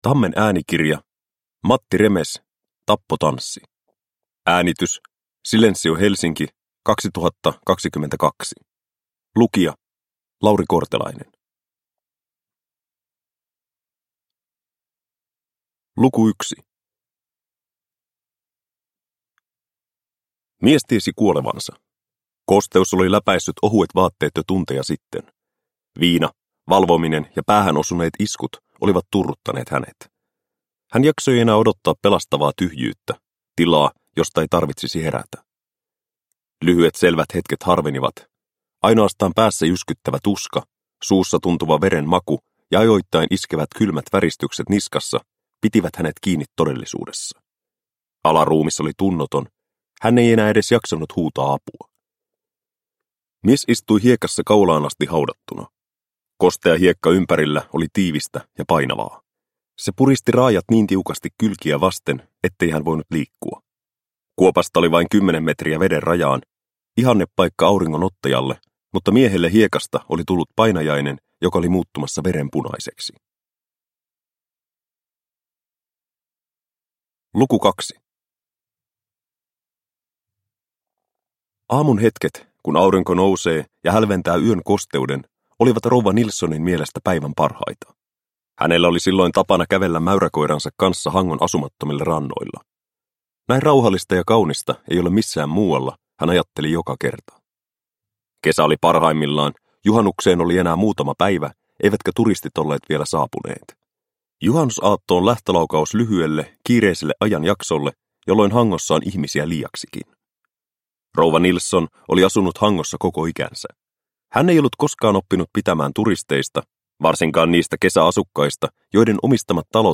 Tappotanssi – Ljudbok – Laddas ner